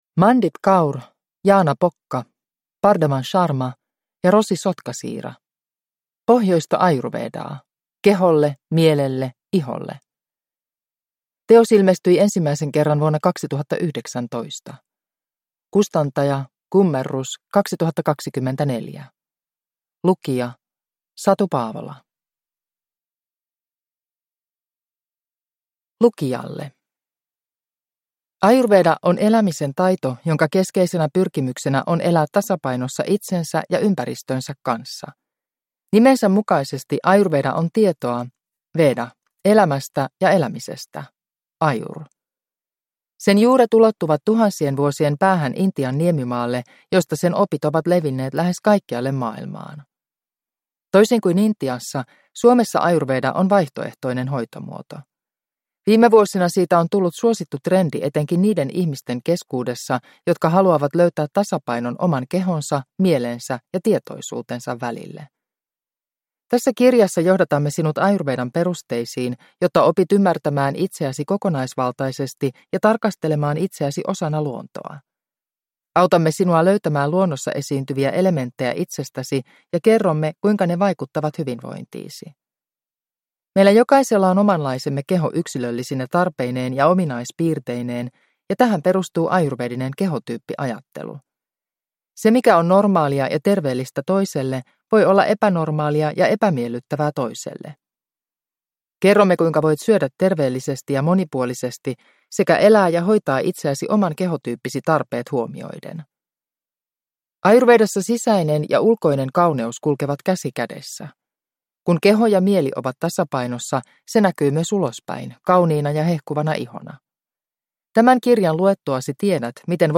Pohjoista ayurvedaa – Ljudbok